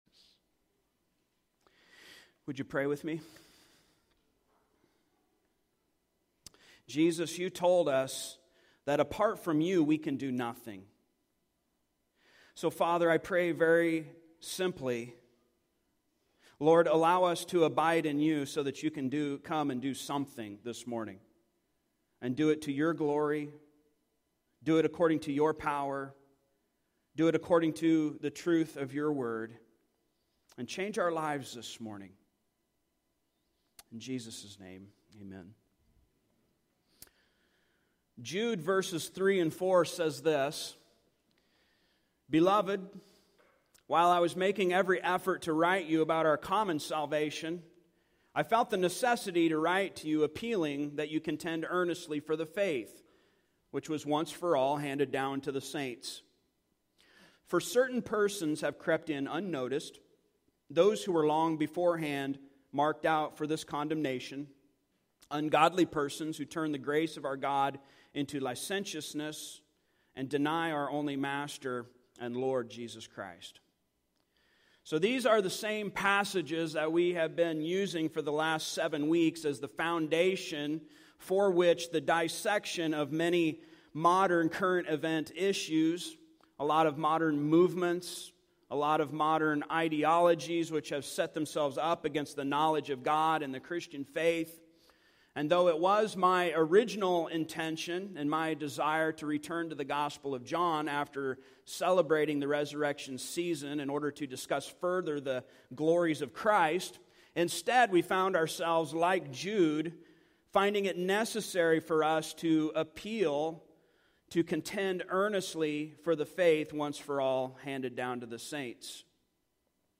Service Type: Sunday Morning Topics: Gospel , Mercy , Repentance